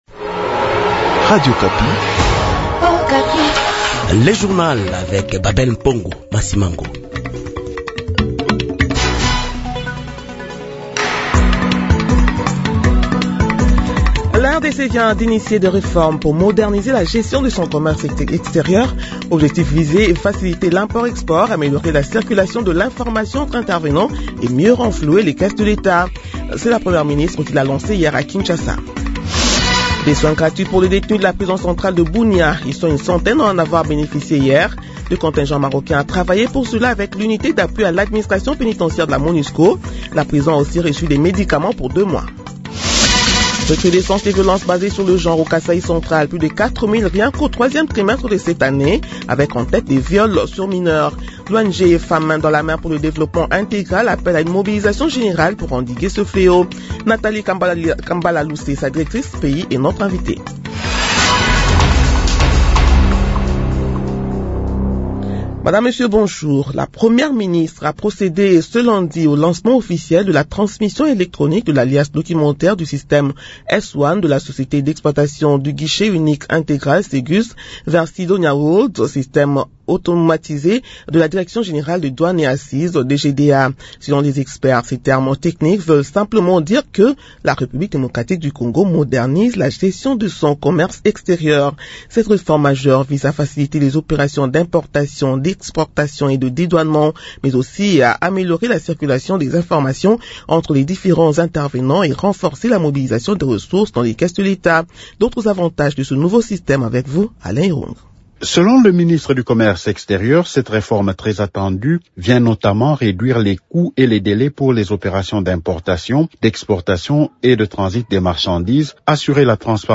Journal 8h de ce mardi 30 décembre 2025